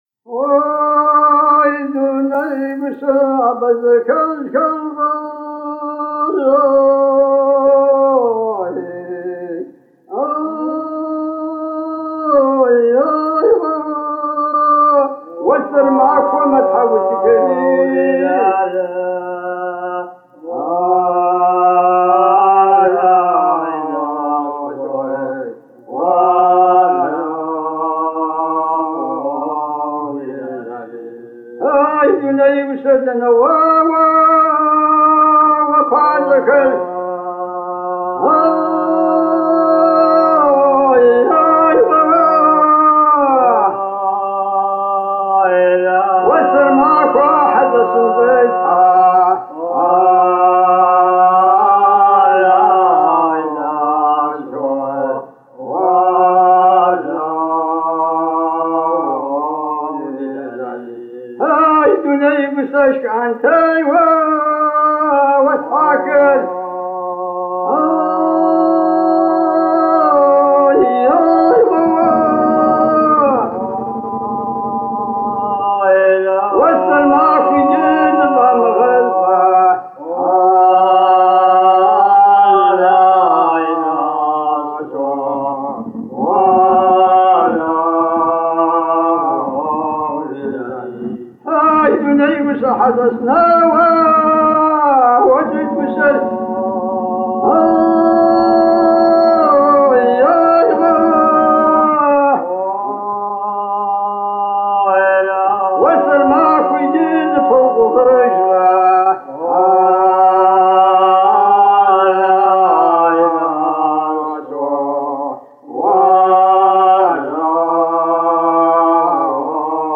Традиция мужского многоголосного пения с бурдоном у адыгов
В любом случае лирико-эпические песни, в которых присутствуют мотивы сетования, оплакивания судьбы и очищения, исполняются в традиционной мужской манере.
Структура сольно-группового пения включает два взаимодействующих пласта — свободную импровизационную мелодию солиста, основанную на поэтическом тексте, и линию хорового сопровождения без смыслонесущего текста.
При этом сочетание высокого регистра сольной партии (как правило, её исполняет тенор или высокий баритон) с низкими голосами хорового сопровождения (в народной терминологии — ежьу) придает песне декламационно-патетический характер.
01 Лирико-эпическая песня «Аслъэнджэри и гъыбзэ» (Песня-плач Аслагери)